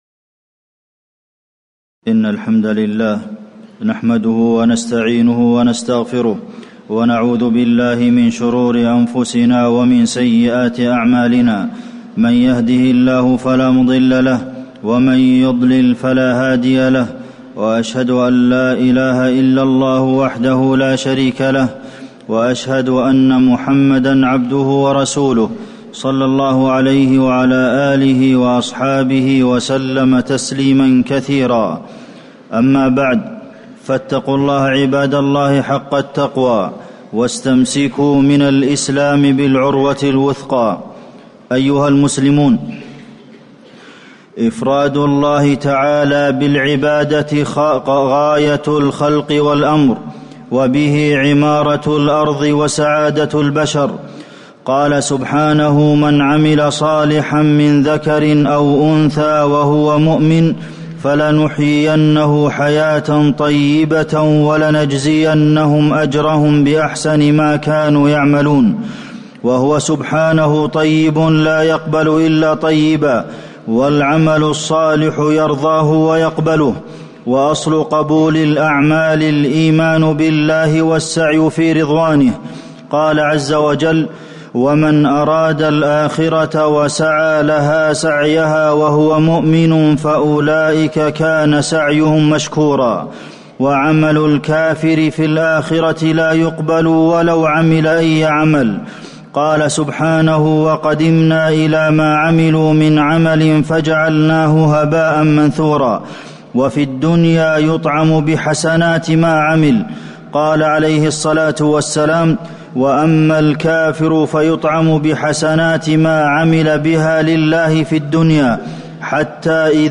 تاريخ النشر ١٧ ذو الحجة ١٤٣٨ هـ المكان: المسجد النبوي الشيخ: فضيلة الشيخ د. عبدالمحسن بن محمد القاسم فضيلة الشيخ د. عبدالمحسن بن محمد القاسم أصل القبول الإخلاص لله عز وجل